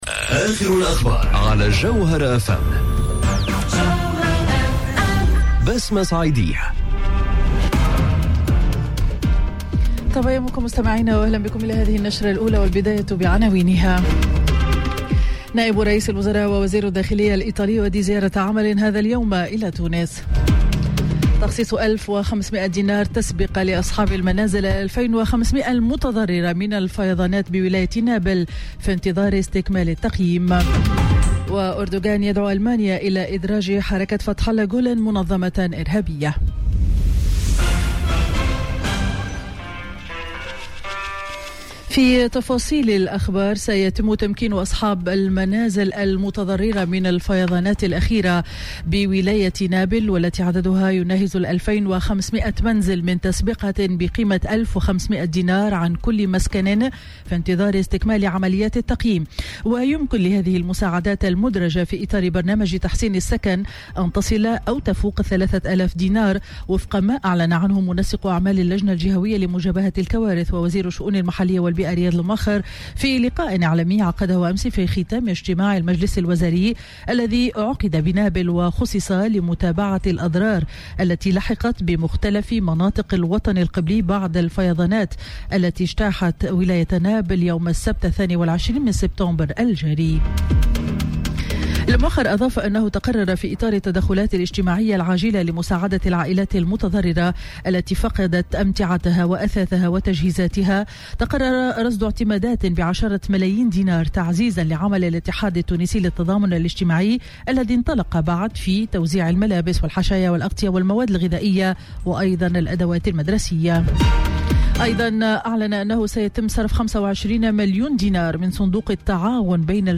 نشرة أخبار السابعة صباحا ليوم الخميس 27 سبتمبر 2018